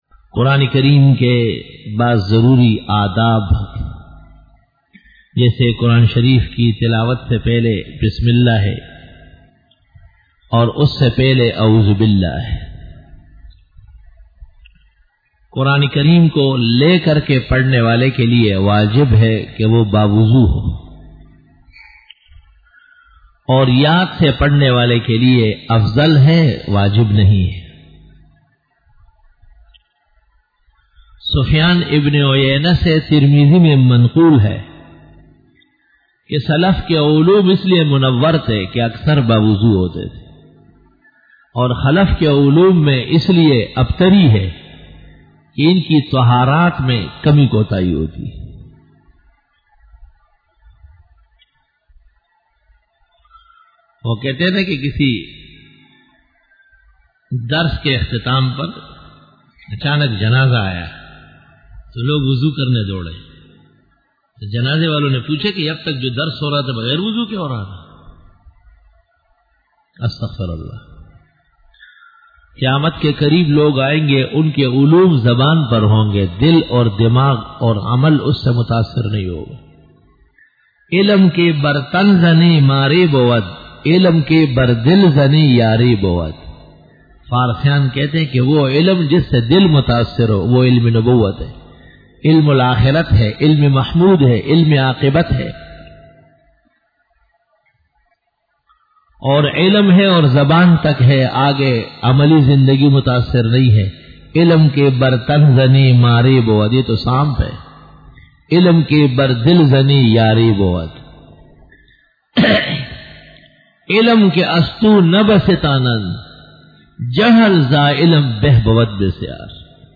بسم اللہ الرحمن الرحیم پر کلام Bayan